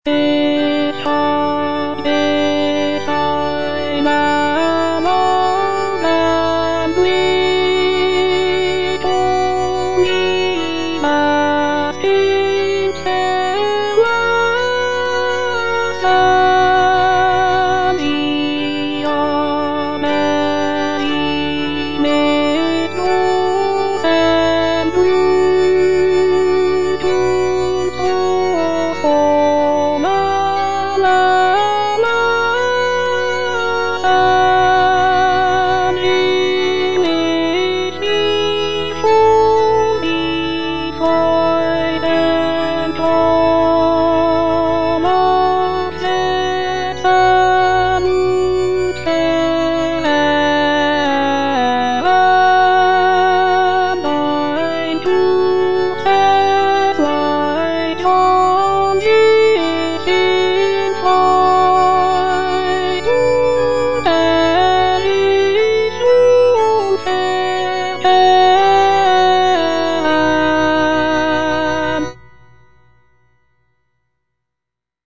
Cantata
Alto (Voice with metronome) Ads stop